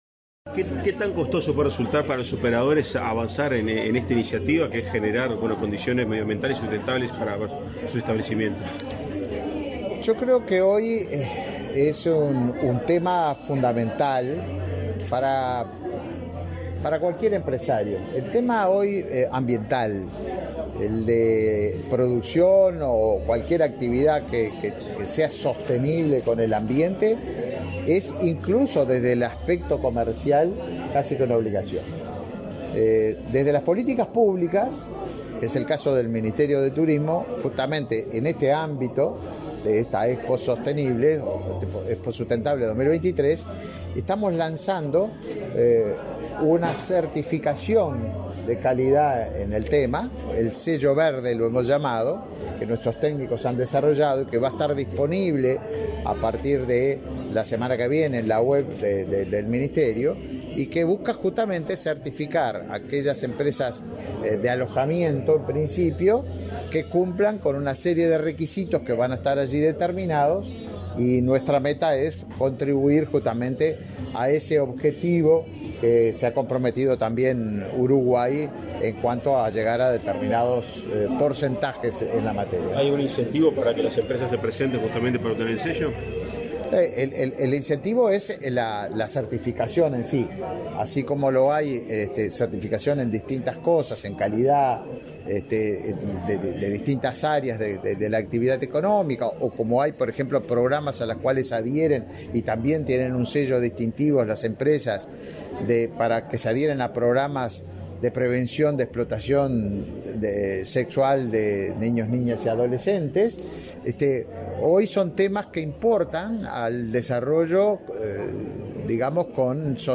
Declaraciones a la prensa del ministro de Turismo, Tabaré Viera
Declaraciones a la prensa del ministro de Turismo, Tabaré Viera 09/06/2023 Compartir Facebook X Copiar enlace WhatsApp LinkedIn Tras participar en la presentación de medidas para promocionar el turismo, en el marco de la Expo Sostenible 2023, este 9 de junio, el ministro Tabaré Viera realizó declaraciones a la prensa.